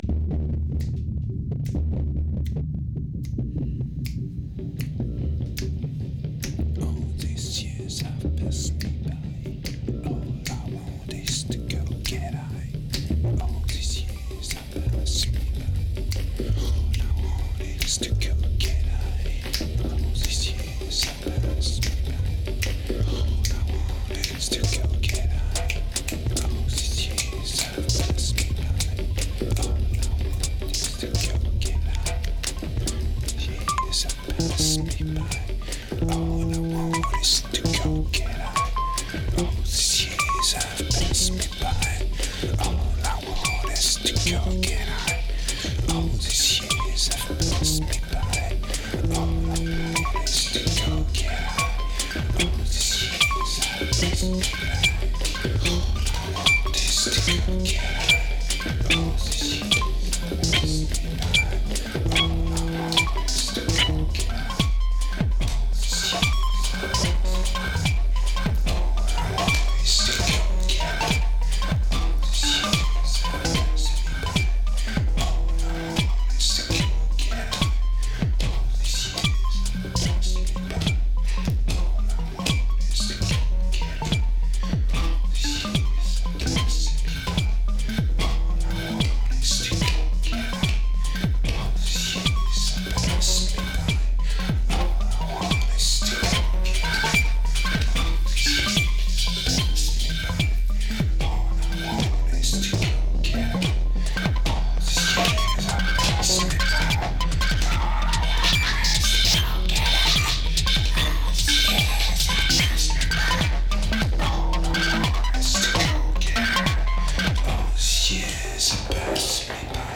a dark album, fast and poorly recorded during late 2007
Sun ra Aphex Echo Song Minimal Electro Simple Interlude